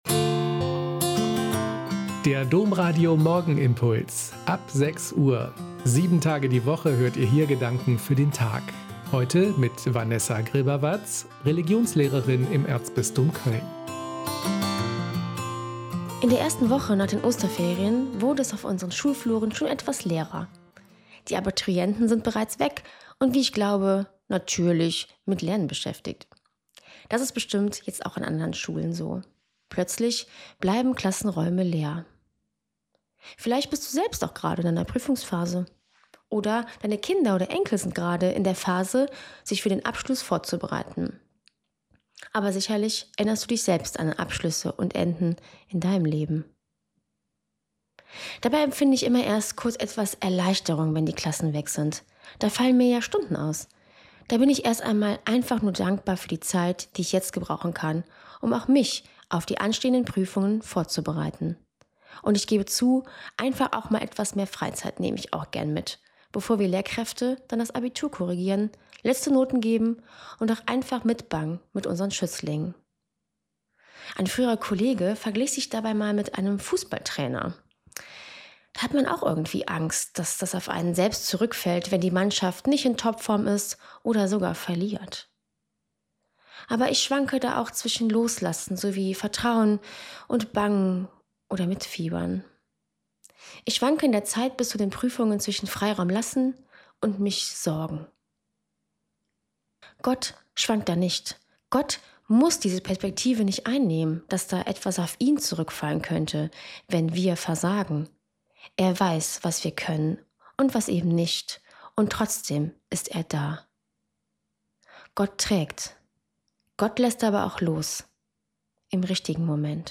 Im Radio um viertel nach 6 Uhr und als Podcast.